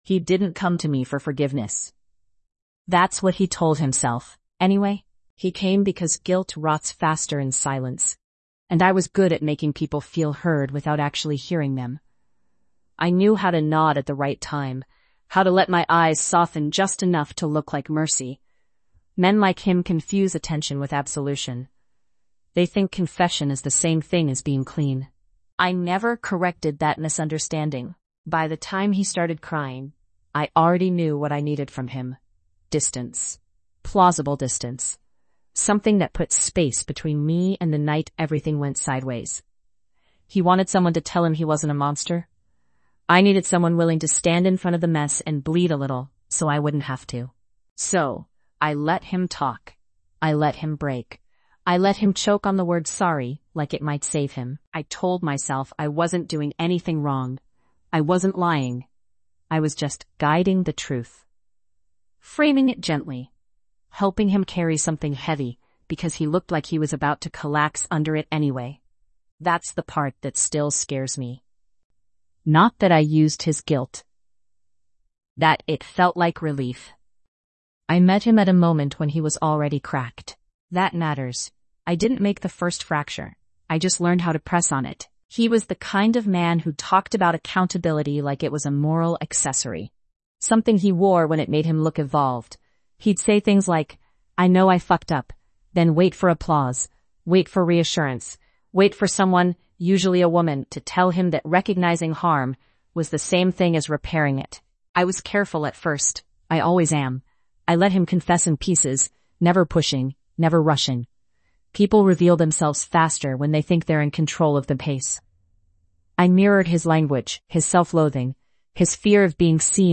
Told entirely from the manipulator’s perspective, this episode explores how guilt can be weaponized, how empathy can be shaped into leverage, and how accountability can be redirected without a single lie being told.